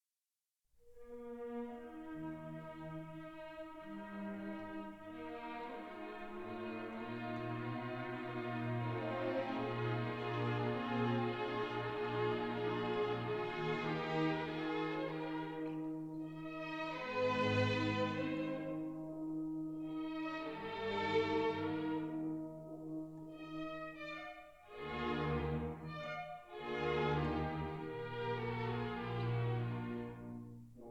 Recorded in the Sofensaal, Vienna on 28 May 1959.